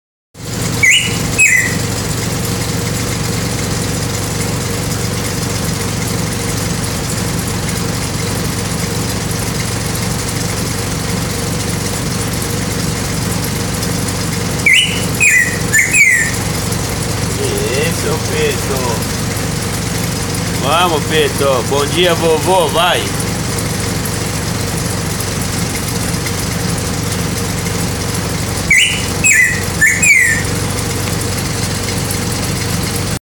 show de ave sai do de muda começando a esquentar